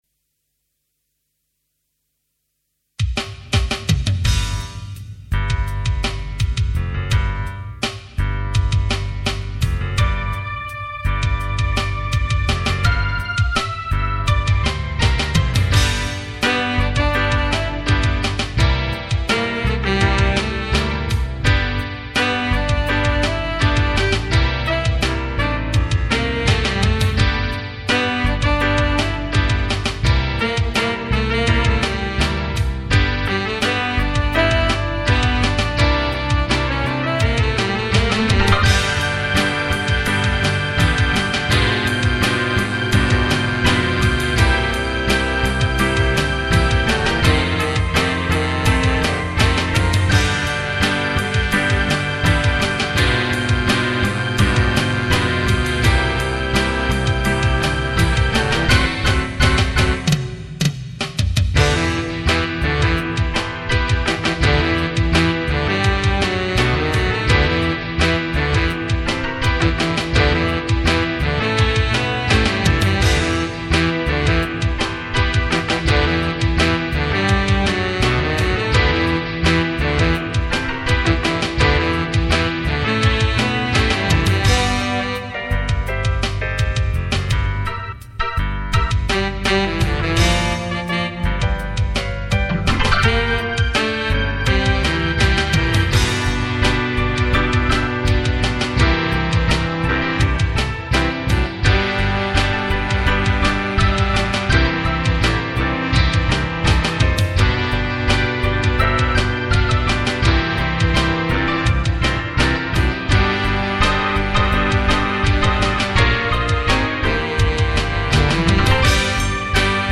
Instrumentation: C, Bb, Eb, pno,Electric Keyboard, Bass
8 piece band arrangement in driving funk style.